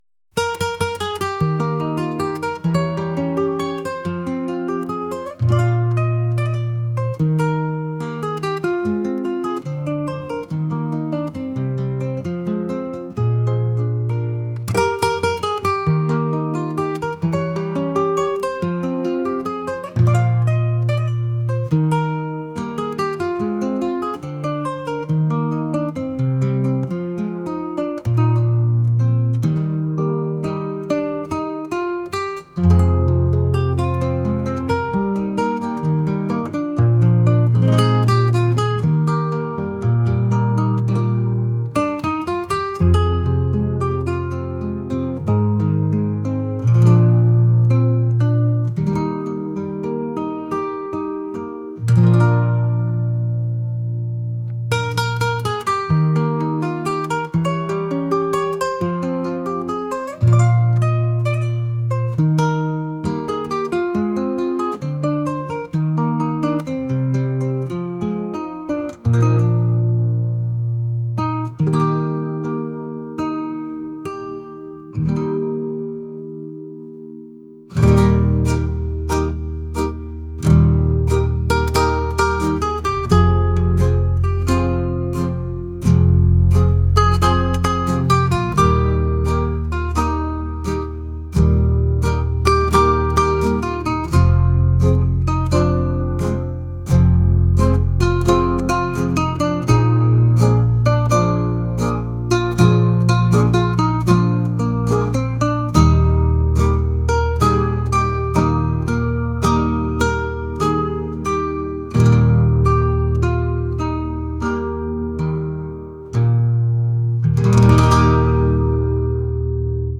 acoustic | folk | laid-back